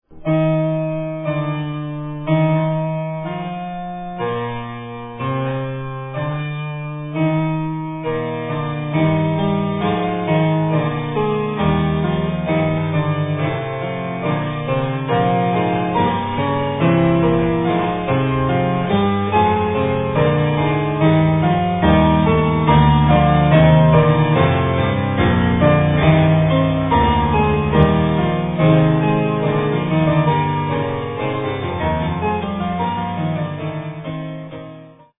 AN HISTORIC REISSUE!